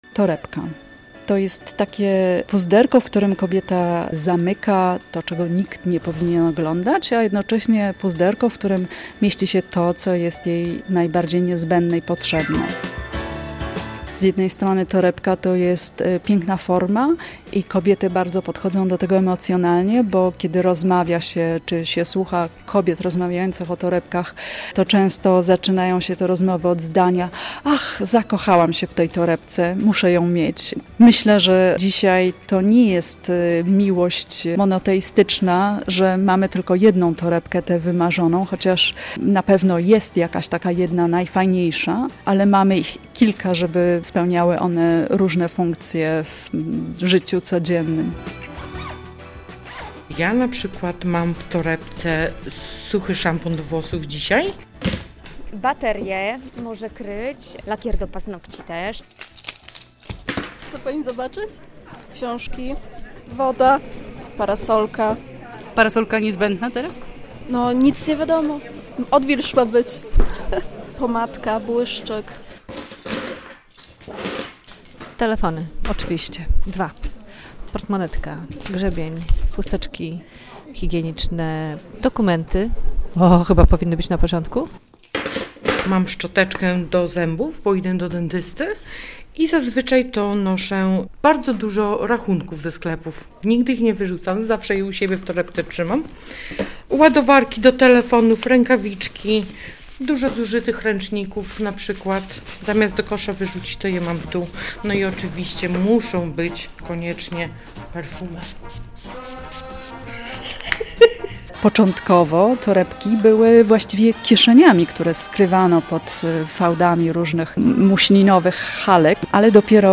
O kulcie torebek rozmawiają same panie